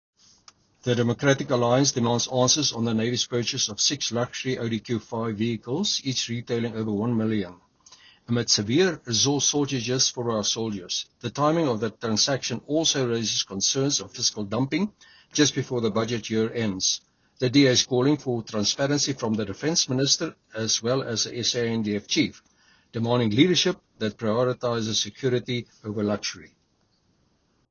Please find attached soundbites in